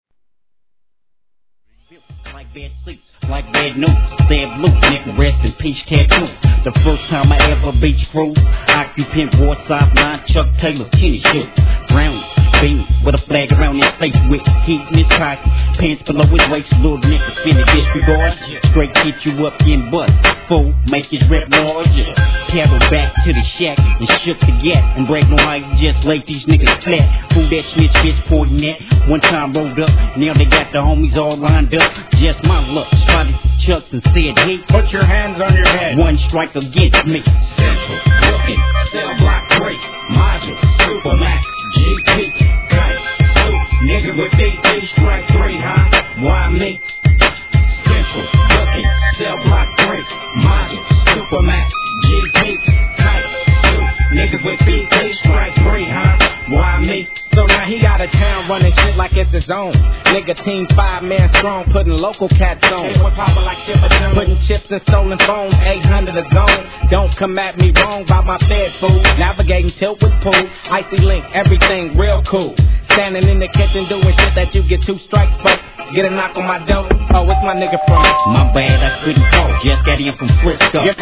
G-RAP/WEST COAST/SOUTH
ネタ使いのグルーヴ感溢れるWEST FUNK!!!